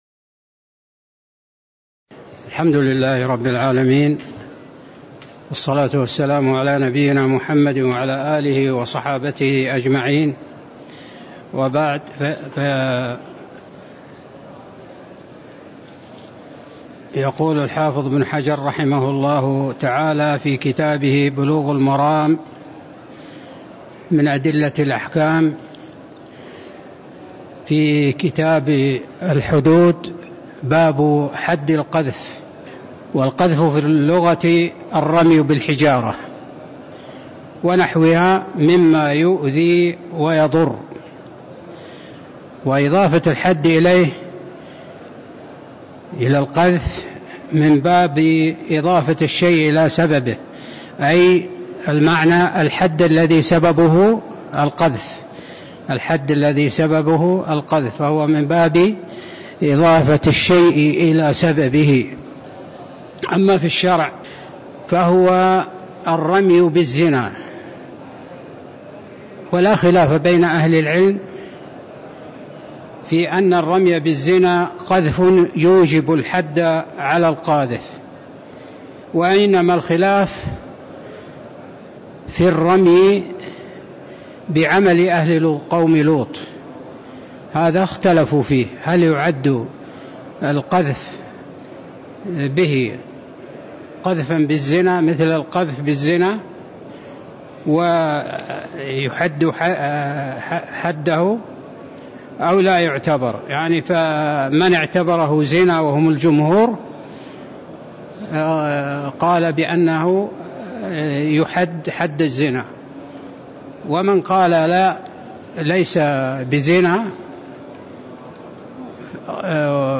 تاريخ النشر ٧ رجب ١٤٤٣ هـ المكان: المسجد النبوي الشيخ